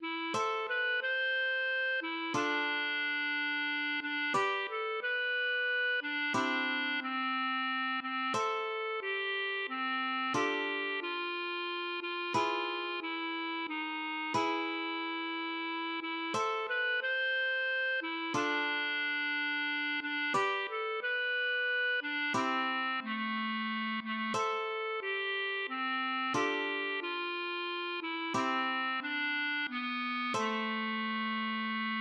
acoustic guitar (nylon)